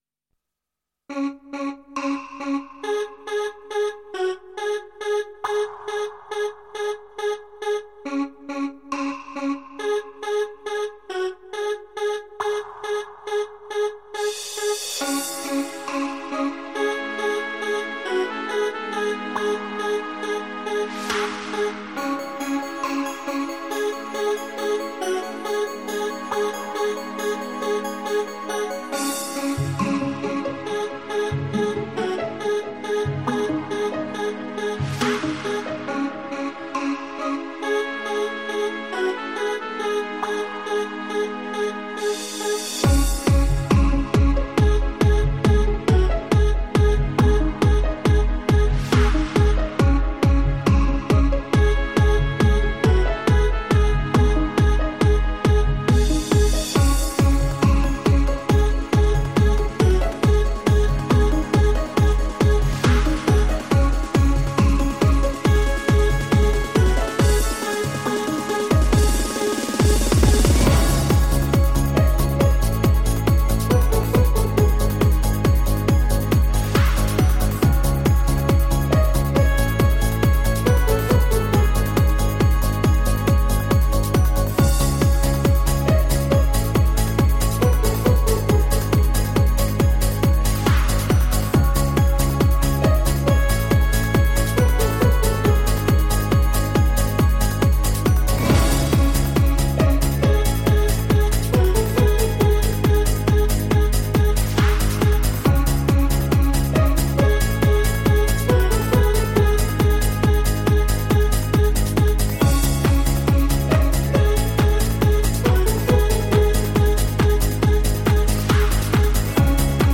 Жанр: Dance/Electronic